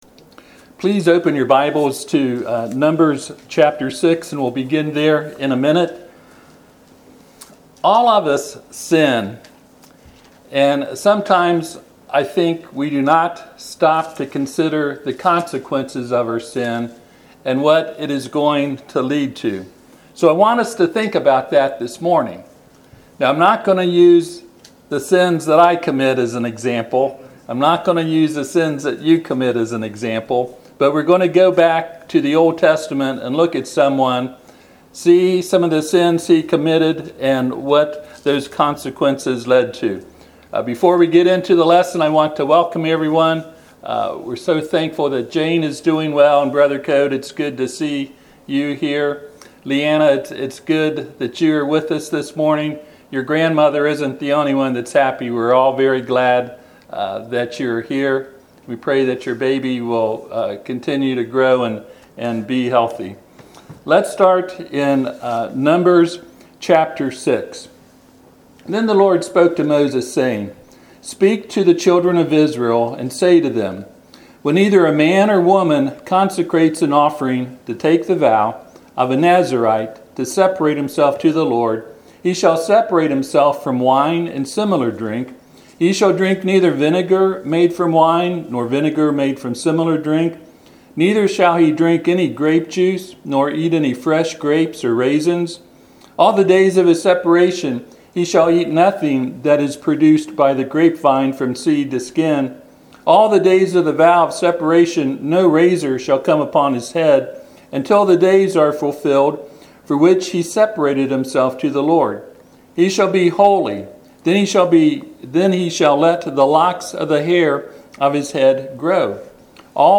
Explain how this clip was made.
Judges 16:25-31 Service Type: Sunday AM Judges 16:30 Then Samson said